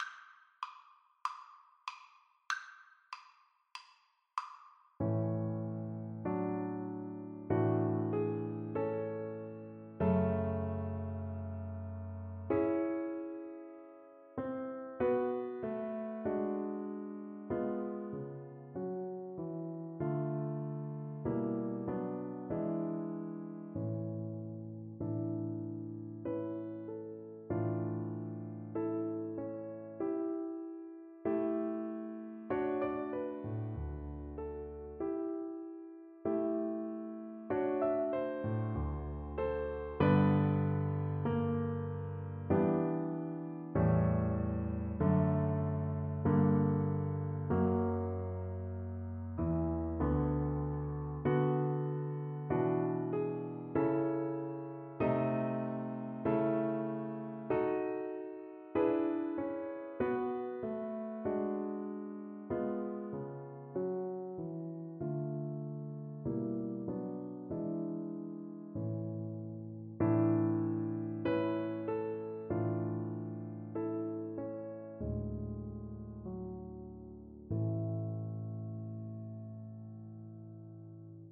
Violin
A major (Sounding Pitch) (View more A major Music for Violin )
4/4 (View more 4/4 Music)
Db5-A6
Somewhat quaintly; not too sentimentally ( = 48)
Classical (View more Classical Violin Music)